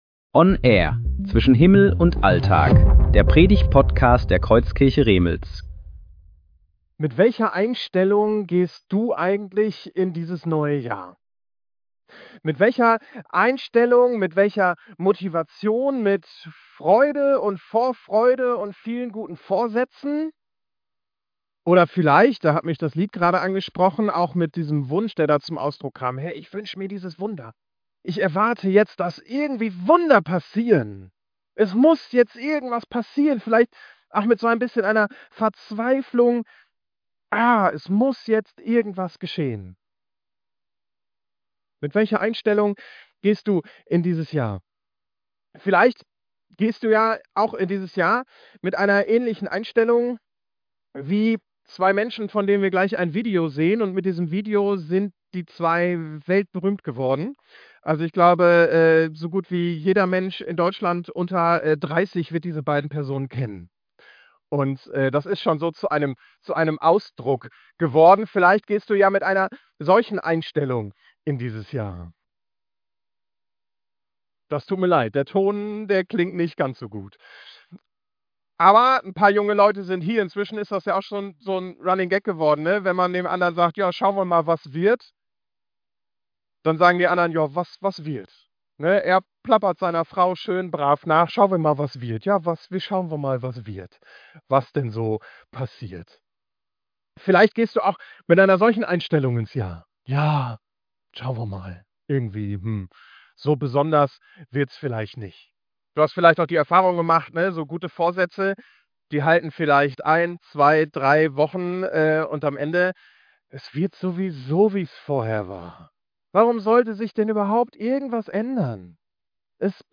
Predigten
Gottesdienst Datum: 11.01.2026 Bibelstelle: Markus 1,14-15 Audio herunterladen